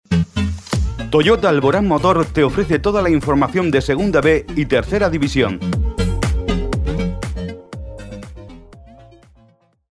español neutro masculino hombre personajes narrador documental comercial
Sprechprobe: Industrie (Muttersprache):